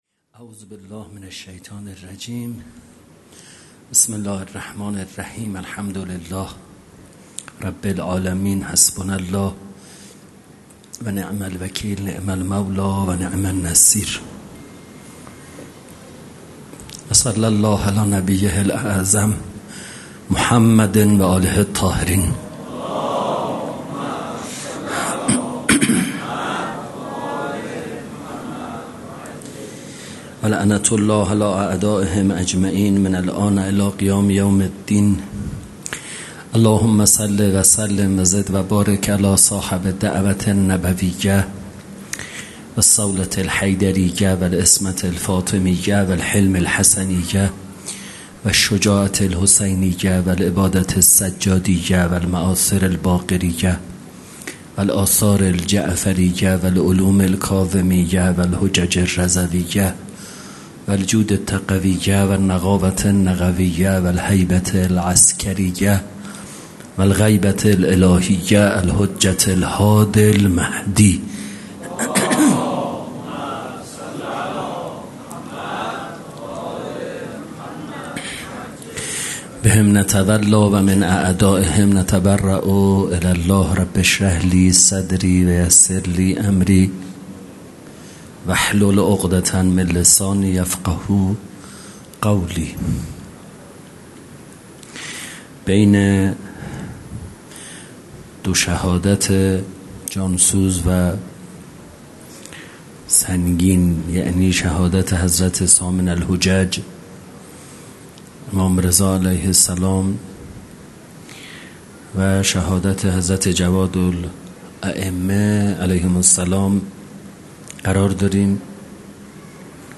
جلسه هفتگی | شهادت امام جواد علیه‌السلام ۱۳۹۸